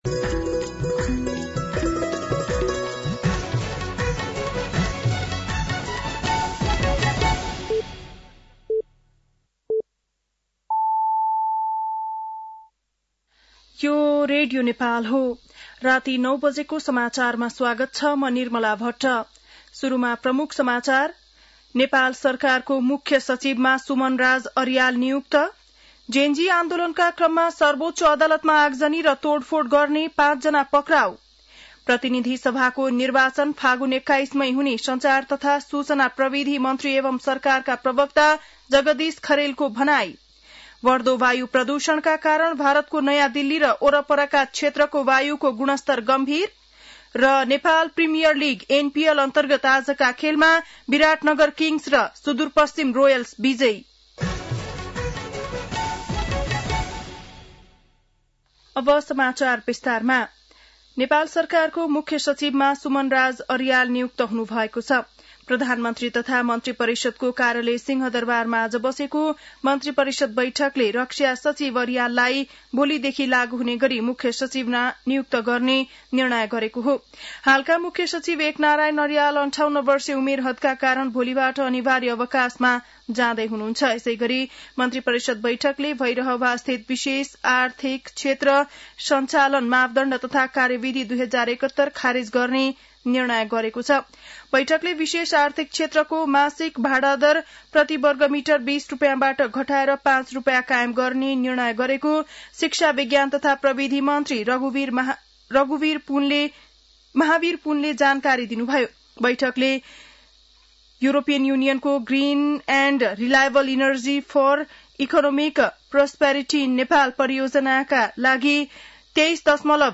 बेलुकी ९ बजेको नेपाली समाचार : ८ मंसिर , २०८२
9-PM-Nepali-NEWS-8-8.mp3